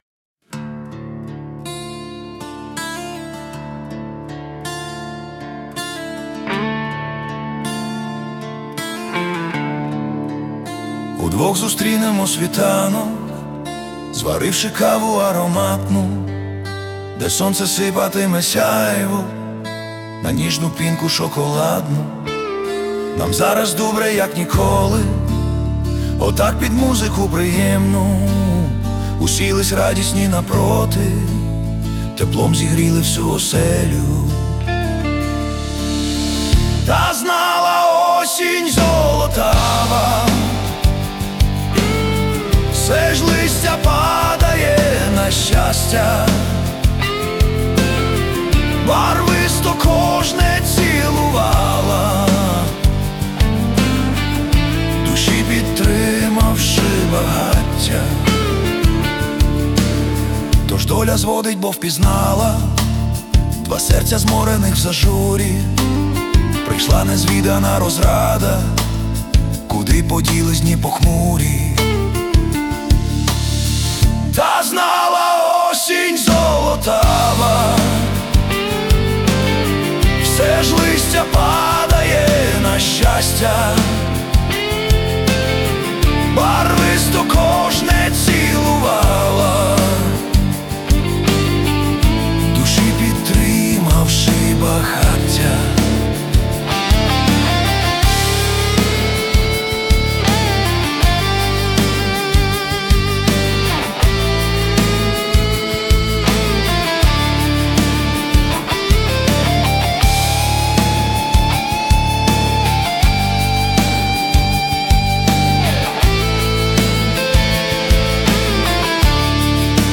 Музична композиція створена за допомогою SUNO AI
СТИЛЬОВІ ЖАНРИ: Ліричний
Чудова, мелодійна пісня кохання. 16 12 22 give_rose